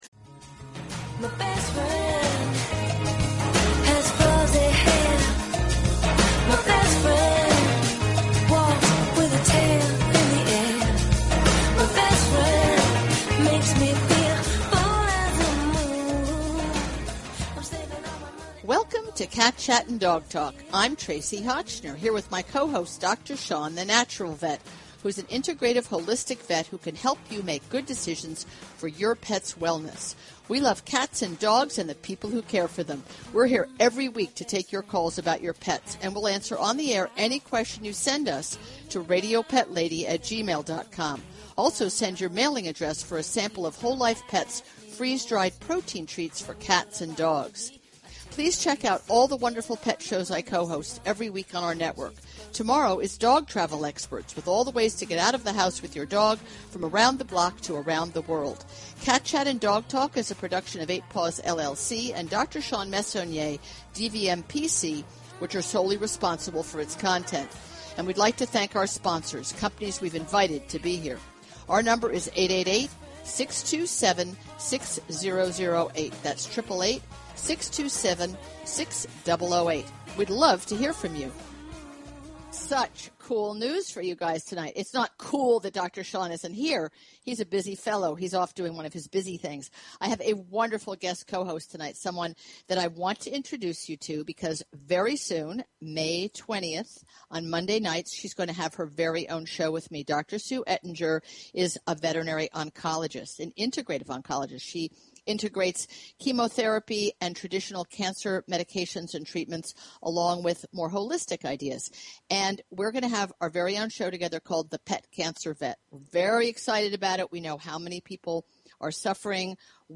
Talk Show Episode, Audio Podcast, Cat_Chat_and_Dog_Talk and Courtesy of BBS Radio on , show guests , about , categorized as